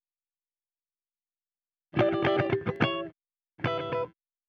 Retro Funkish Guitar 01a.wav